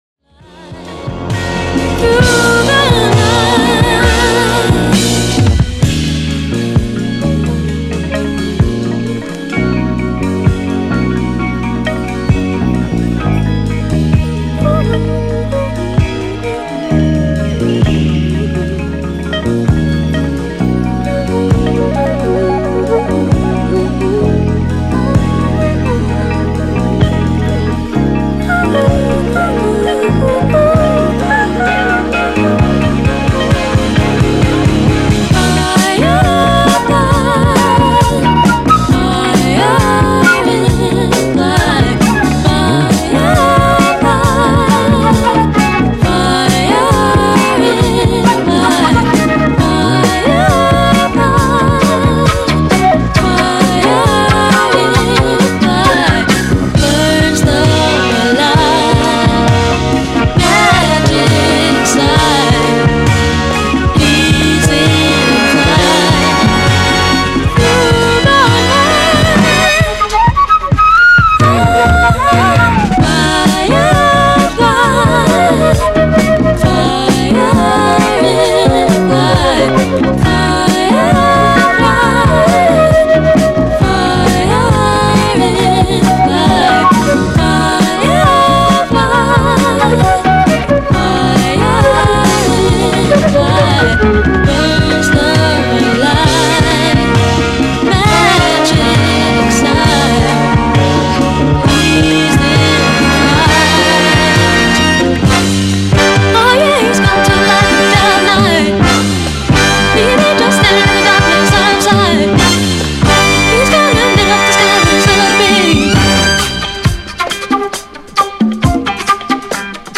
プログレッシヴ・フルート！
• 特記事項: STEREO